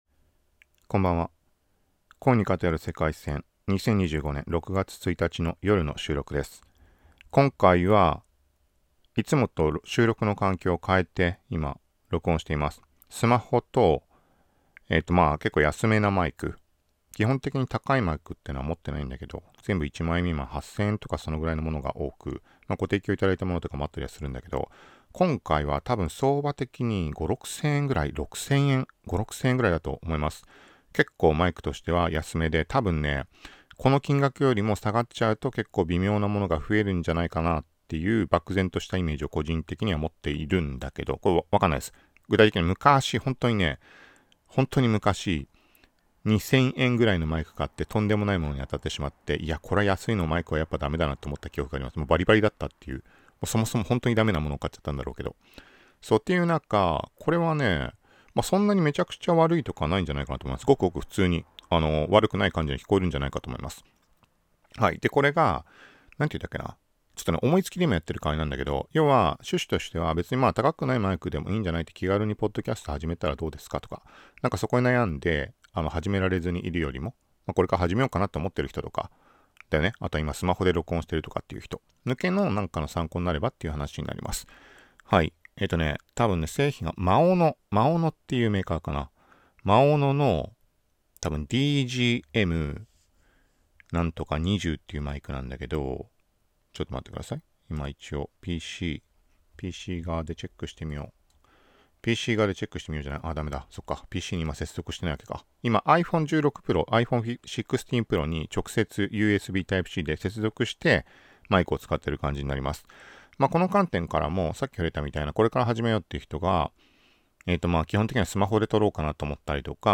cheap-microphone-maono-dgm20-sound-review-for-podcast.m4a